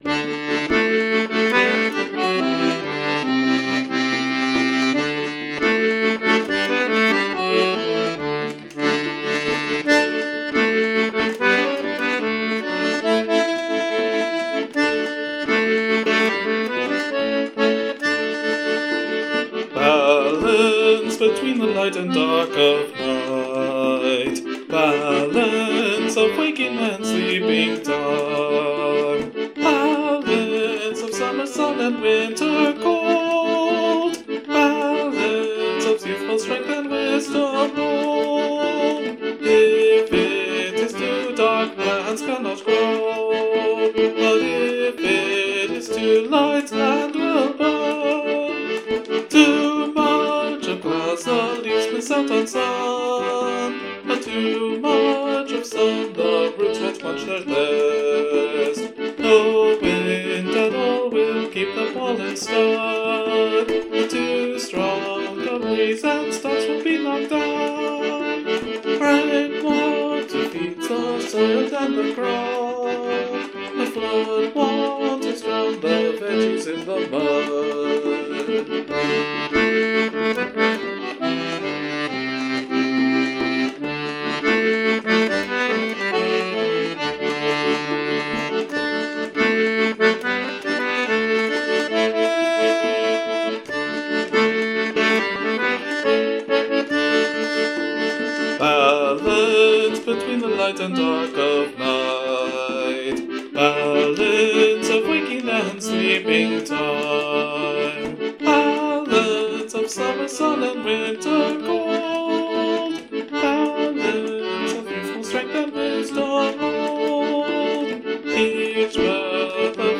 Musically, the melody isn't that difficult, and the harmonies pretty standard.
At least it's somewhat consistent: The chorus is 3+3+1+4+2+2+8/8, while the verses are 3+3+1+2+2+4+8/8, in both cases adding up to 23/8, or not quite as much time as you expect.
So yes, that will feel a little bit off-kilter, which demonstrates to me the value of balancing things out by being so unbalanced.